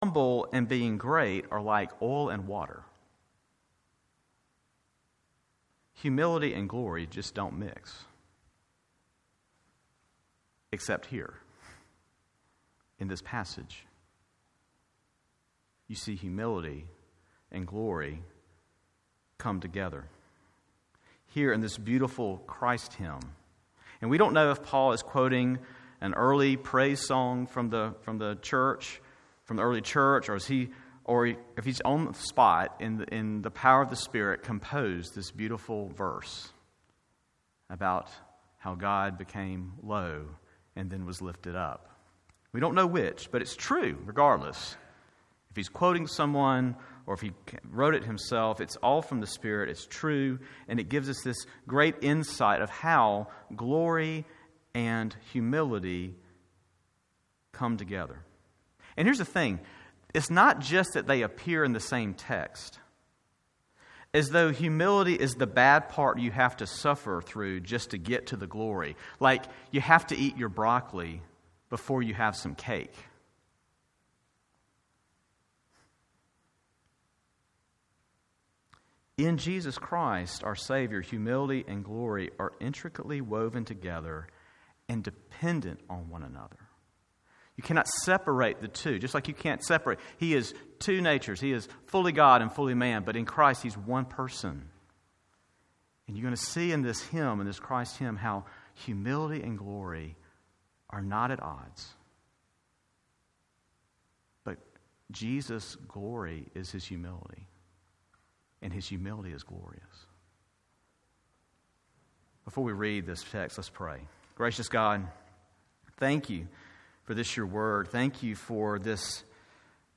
Faith Presbyterian Church PCA Sermons